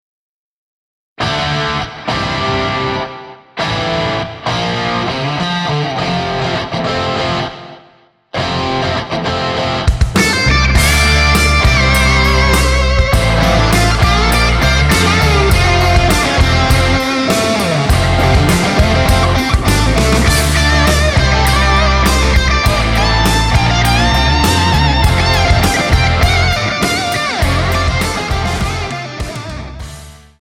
--> MP3 Demo abspielen...
Tonart:F ohne Chor